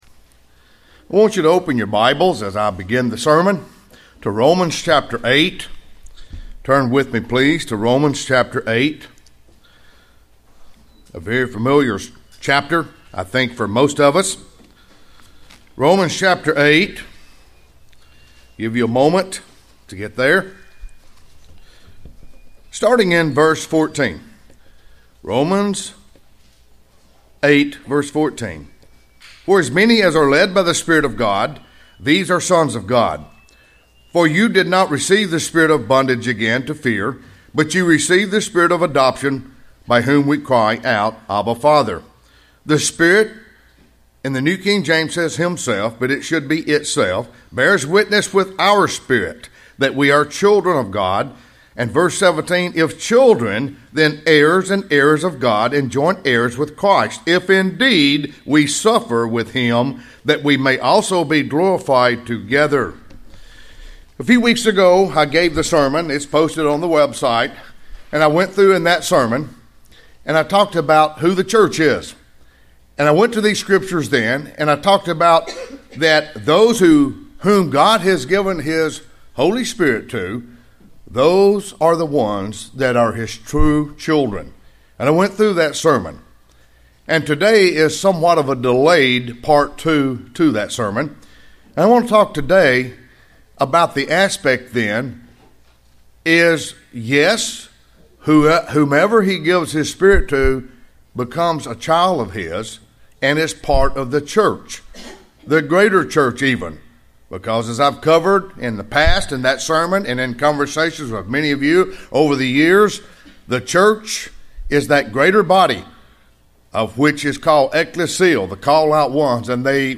This is a message that looks at who receives the Holy Spirit, and when it is given and how it comes to dwell within an individual.